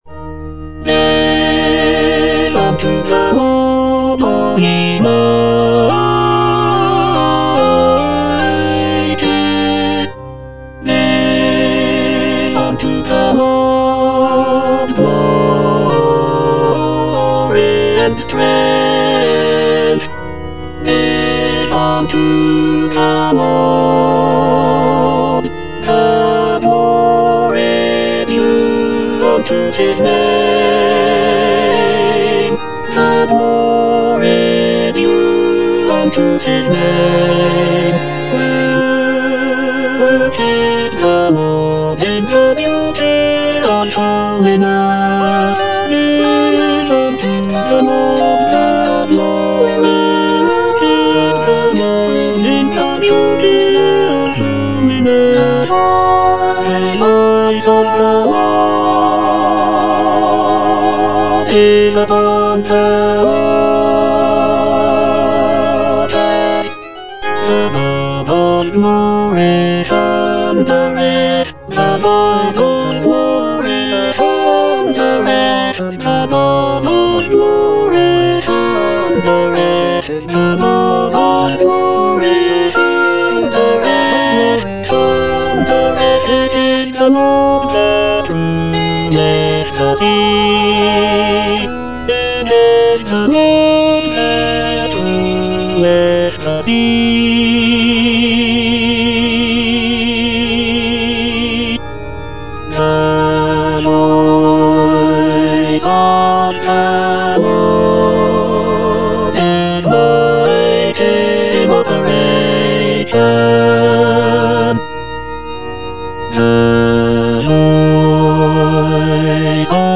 Tenor I (Emphasised voice and other voices)